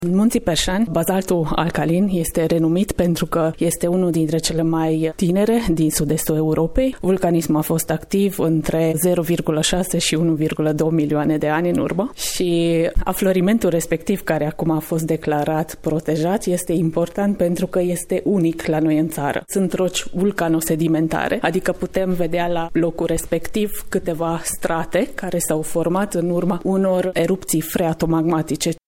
Geolog